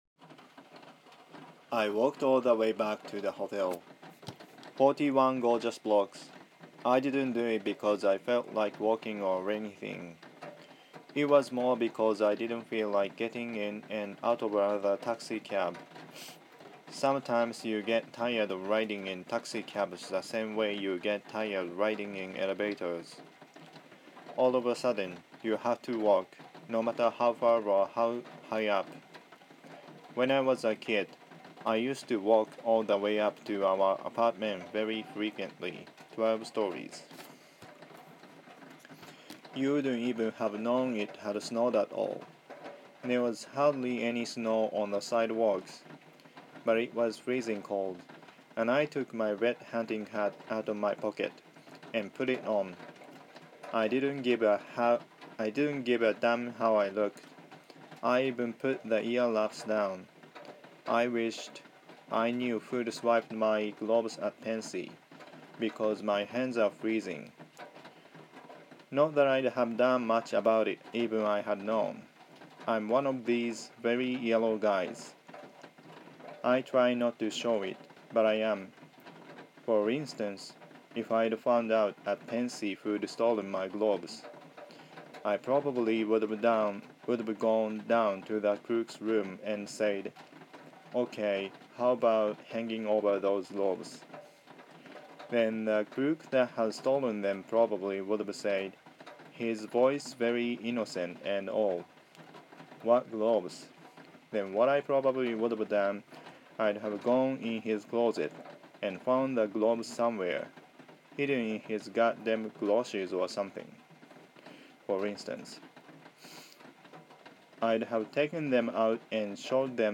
Catcher in the Rye(朗読)